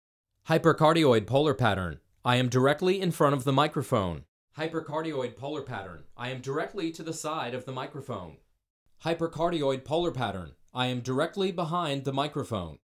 Hypercardioid
There is a small node of pickup from behind the microphone capsule, which you’ll hear in the audio example.
vocals-hypercardioid-waveinformer.mp3